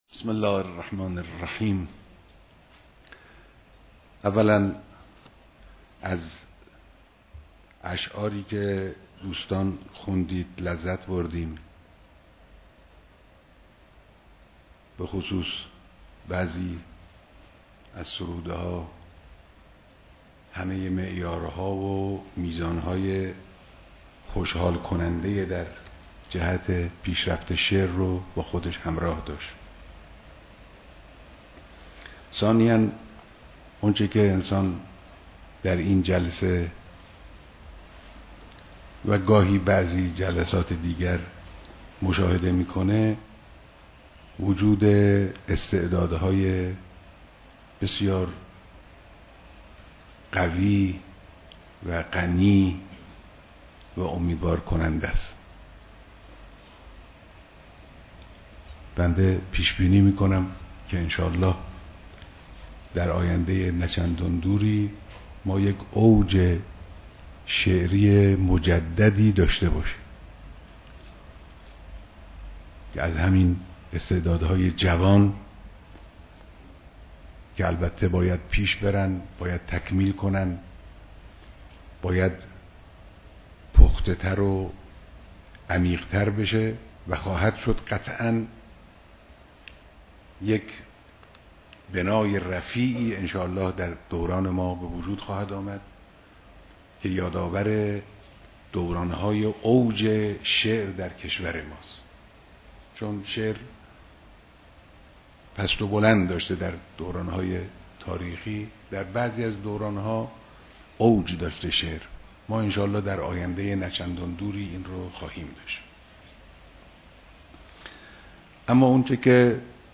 بيانات در ديدار جمعى از شعراى آئينى